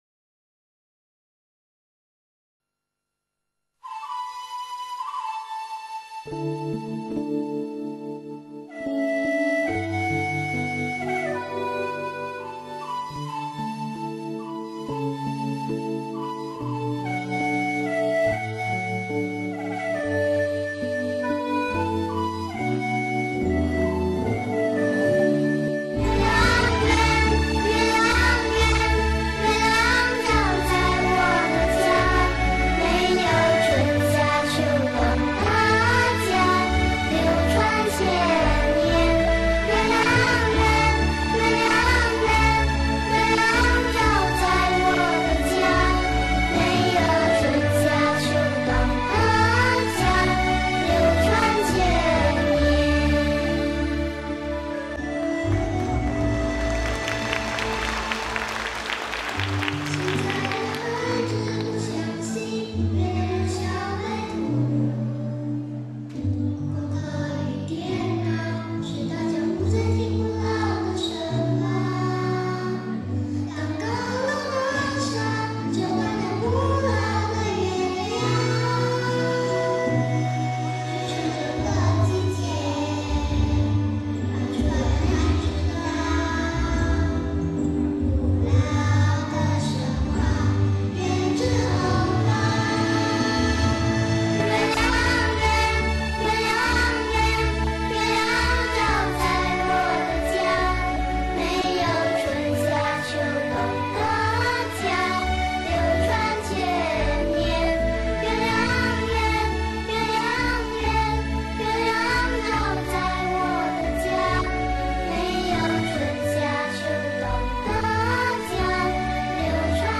视频：欢庆中秋新加坡佛友庆中秋，小佛友合唱“我们的月亮一定圆 我们永远心相连”